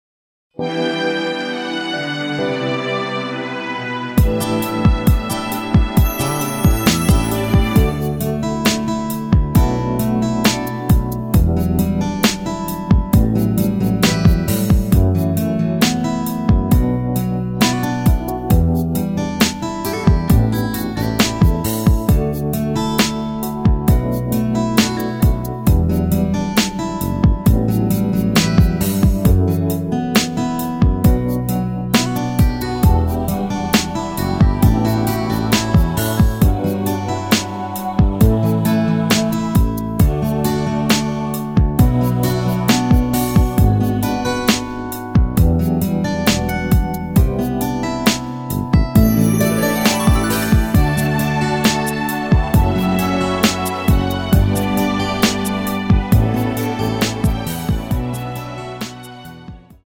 축가로도 잘 어울리는 곡
앞부분30초, 뒷부분30초씩 편집해서 올려 드리고 있습니다.
중간에 음이 끈어지고 다시 나오는 이유는